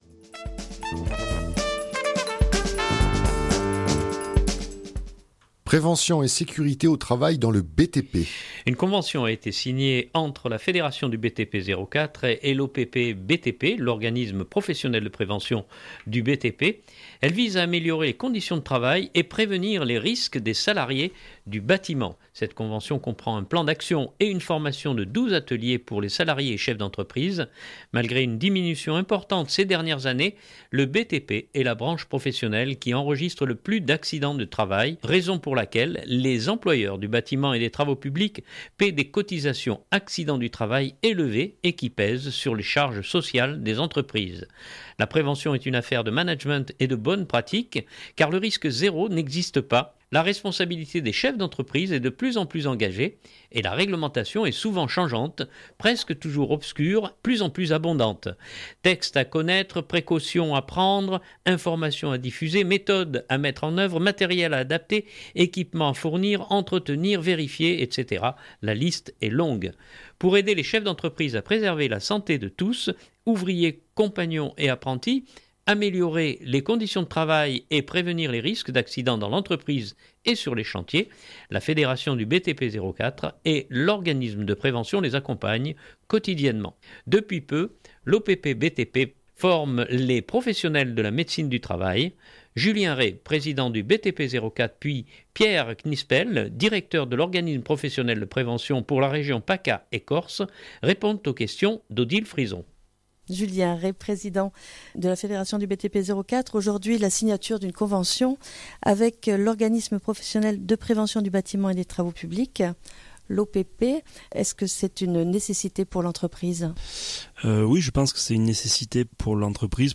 répondent aux questions